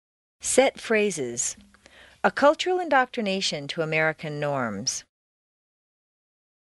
美语口音训练第一册40 听力文件下载—在线英语听力室